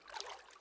water1.wav